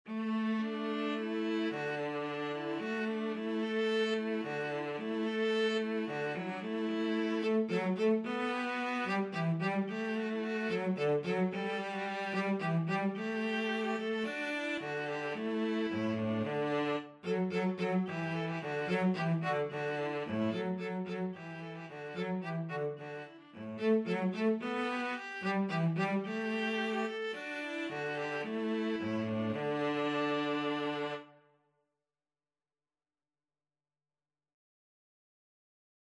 Tempo Marking:
Classical (View more Classical Viola-Cello Duet Music)